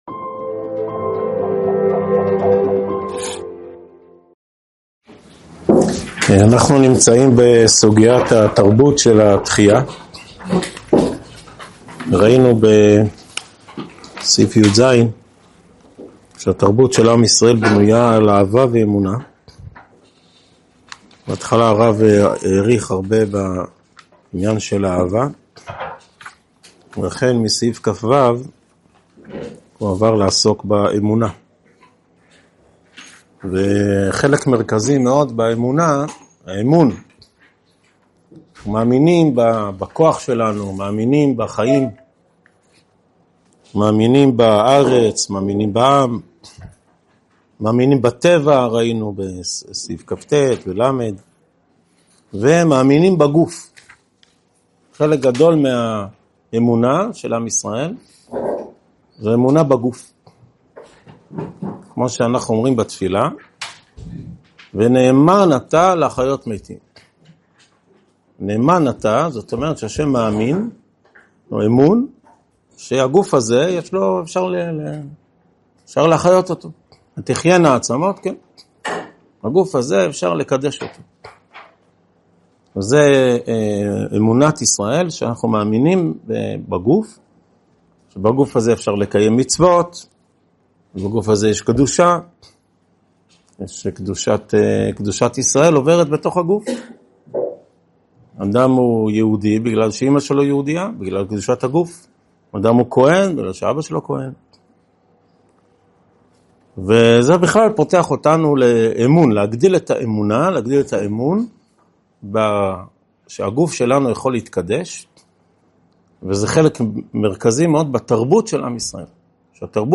הועבר בישיבת אלון מורה בשנת תשפ"ה.